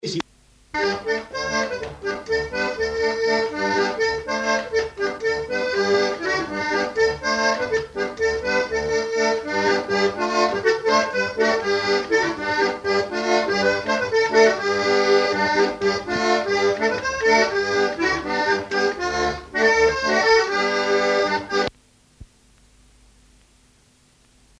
Air de bourrée à l'accordéon
Localisation Douarnenez
danse : bourree
Pièce musicale inédite